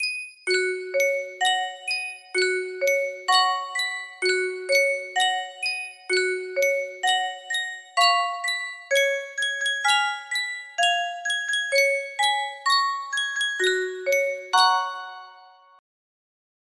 Sankyo Music Box - It's Raining It's Pouring GAR music box melody
Full range 60